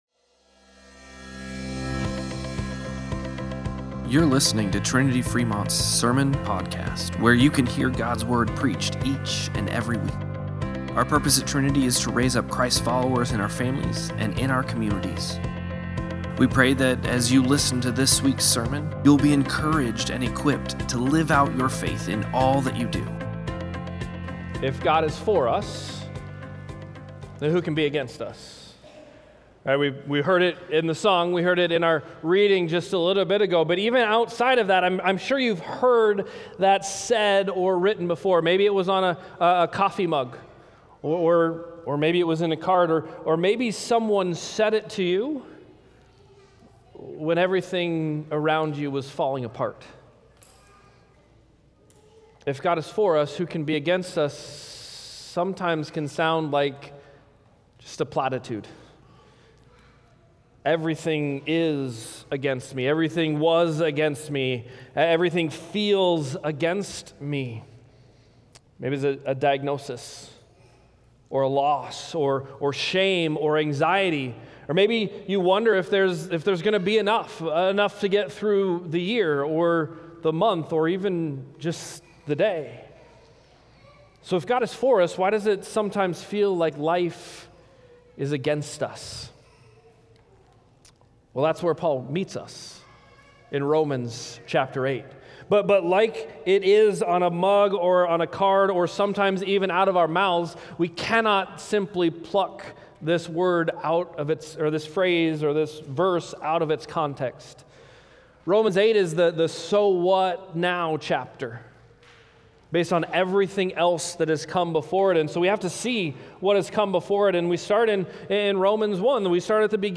Sermon-Podcast-07-13.mp3